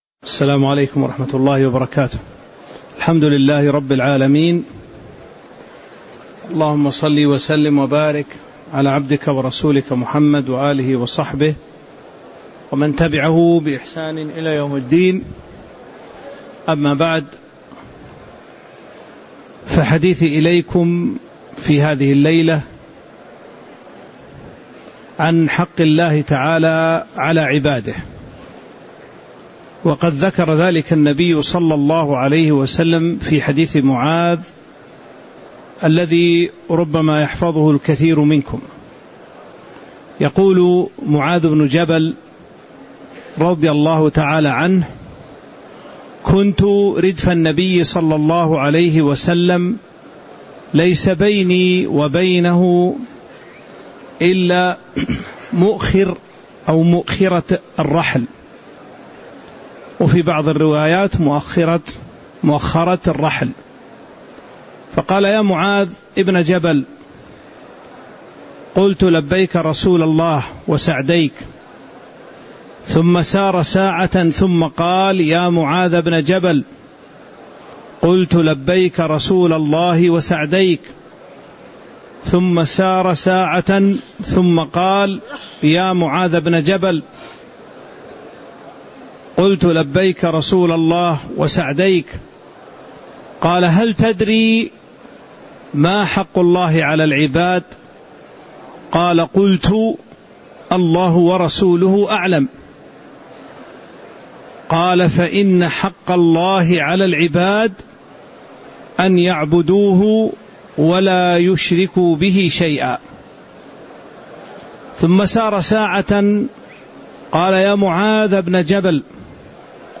تاريخ النشر ١٦ ذو الحجة ١٤٤٦ هـ المكان: المسجد النبوي الشيخ